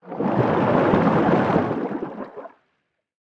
Sfx_creature_arcticray_swim_twist_03.ogg